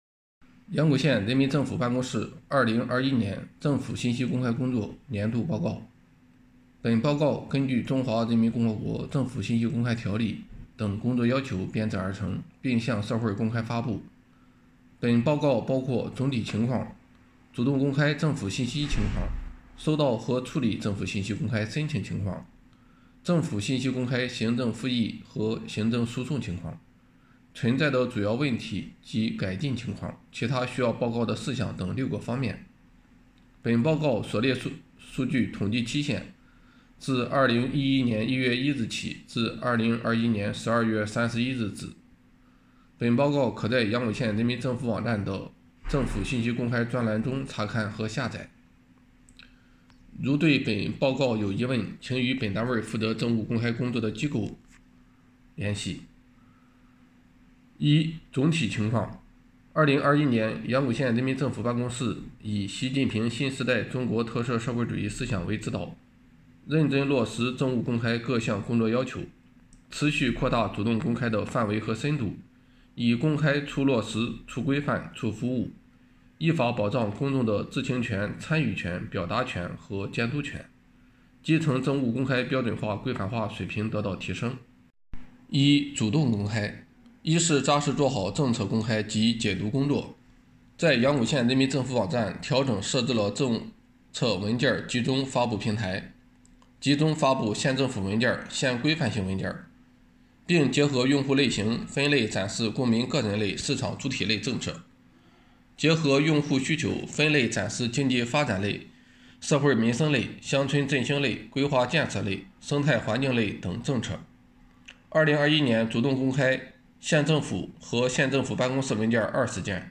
阳谷县人民政府办公室2021年政府信息公开工作年度报告（有声朗读）.mp3